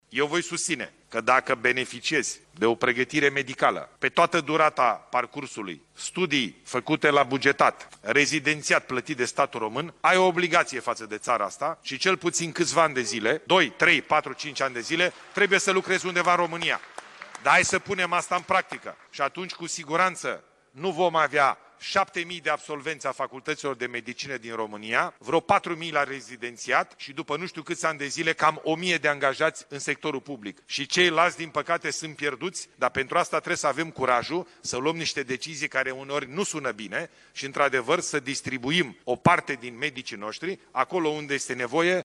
Declarația a fost făcută în fața primarilor de comune din țară, care s-au plâns primului ministru de lipsa medicilor în mediul rural sau în comunitățile izolate.